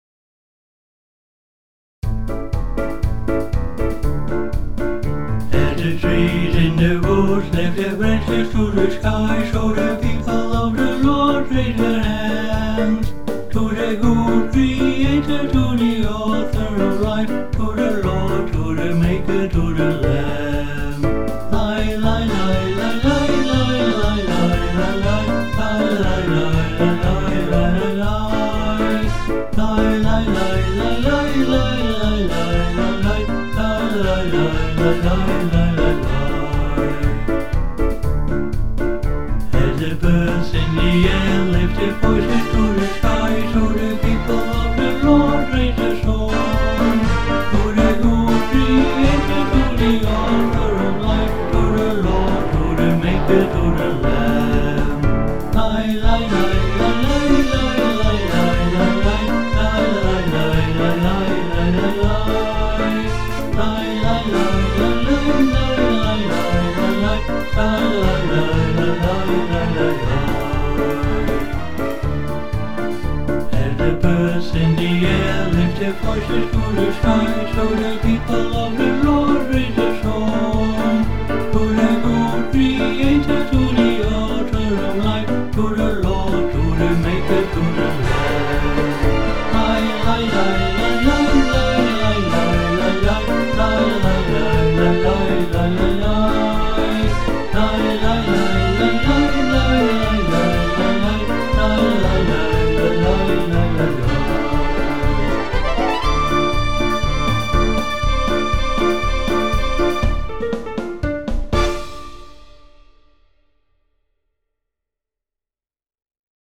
a lively Messianic chorus
Karaoke Video with vocal